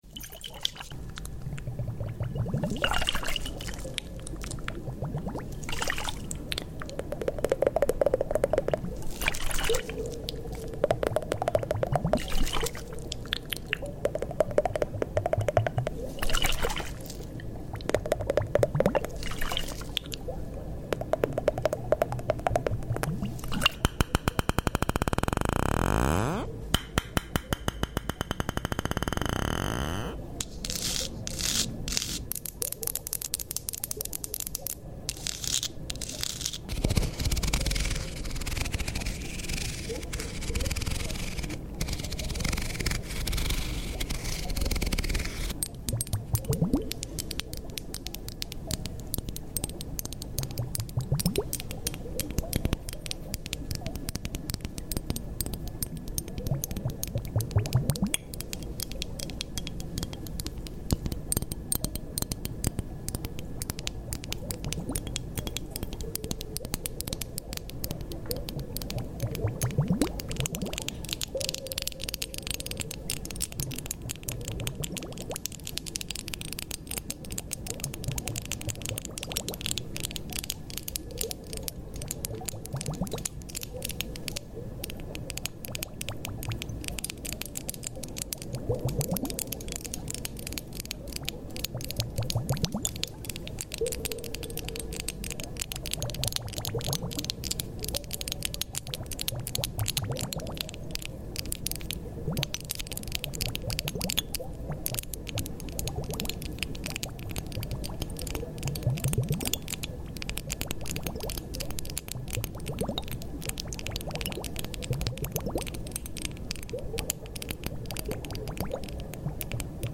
Ice globe under water Asmr sound effects free download